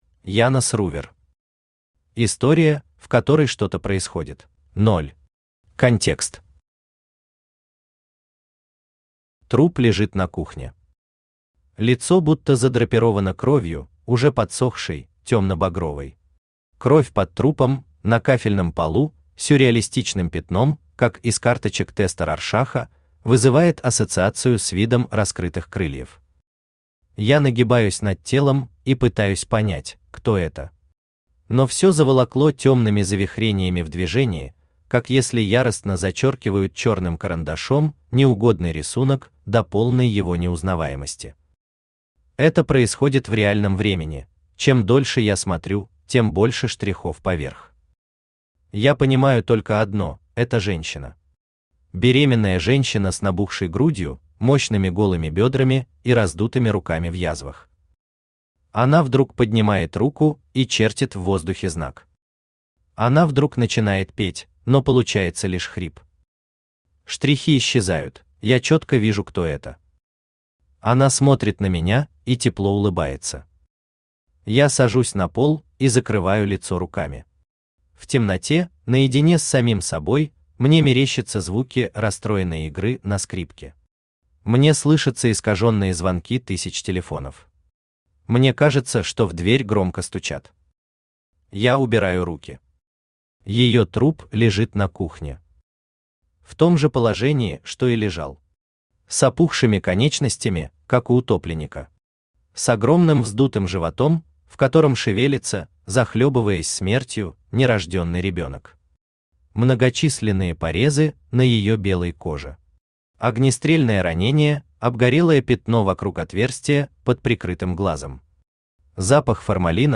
Аудиокнига История, в которой что-то происходит | Библиотека аудиокниг
Aудиокнига История, в которой что-то происходит Автор Янос Ли Рувер Читает аудиокнигу Авточтец ЛитРес.